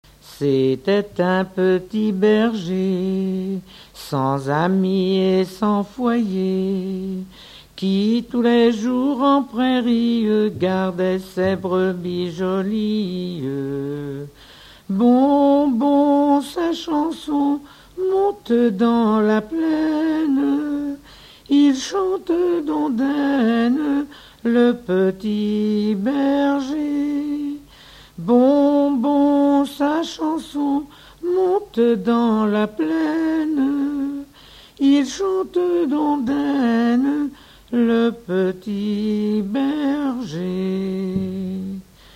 Pastourelle - Chanson du sillon
Genre laisse
chansons traditionnelles
Catégorie Pièce musicale inédite